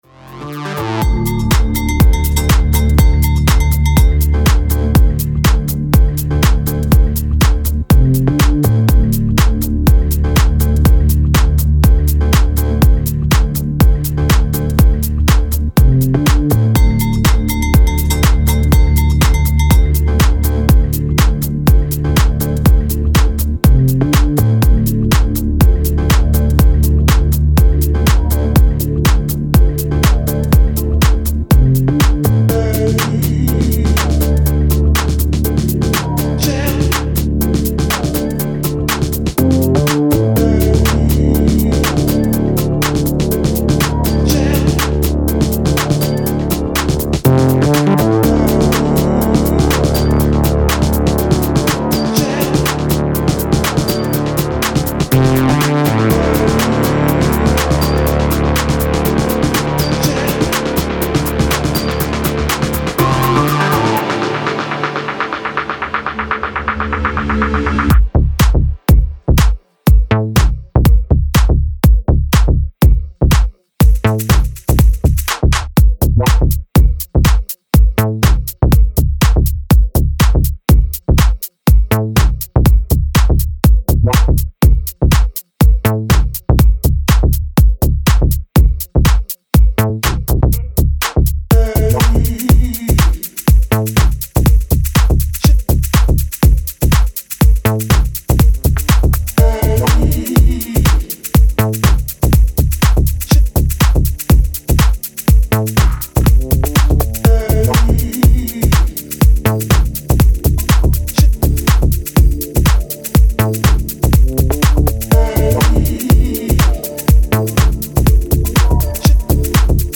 Style: Tech House / Deep House